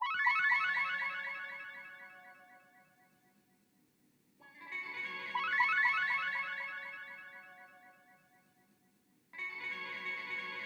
fx_sparkle_90_4